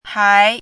chinese-voice - 汉字语音库
hai2.mp3